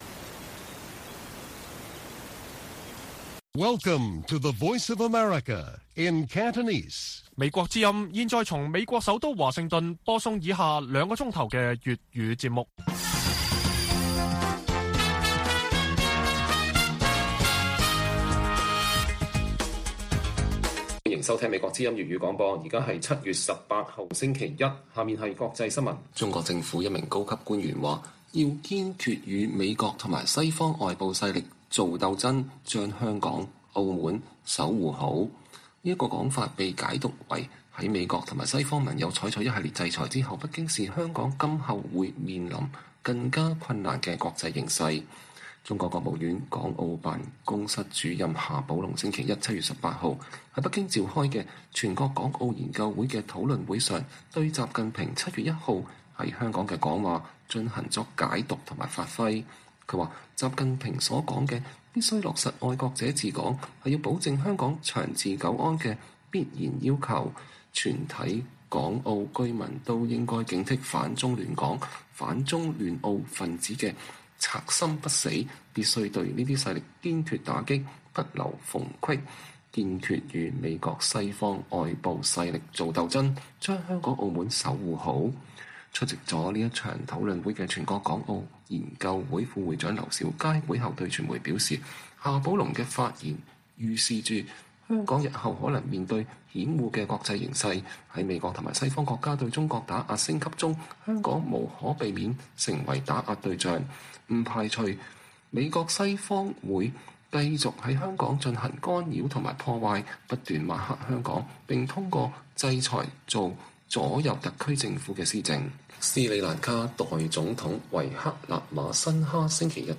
粵語新聞 晚上9-10點: 堵住北京獲取西方科技的最後通道？美以對話升級直指中國